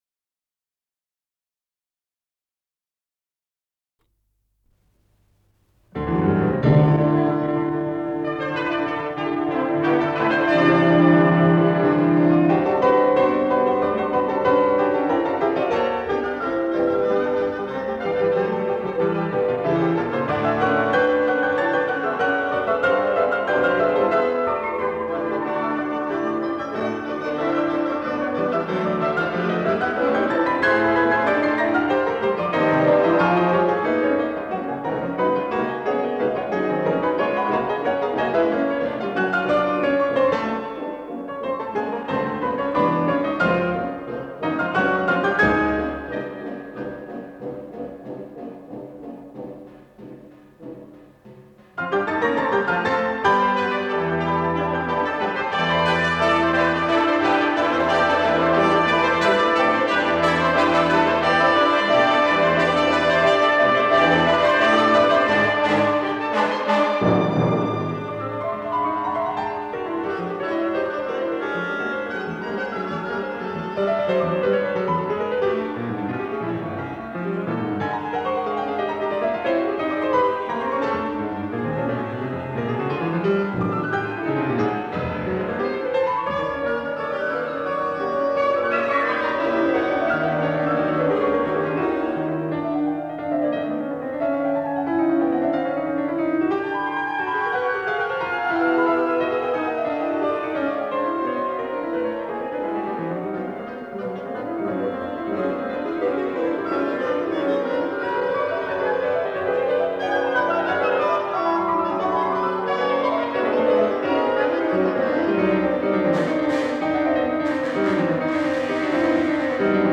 П-06296 — Второй концерт для фортепиано с оркестром — Ретро-архив Аудио
Исполнитель: Золтан Кочиш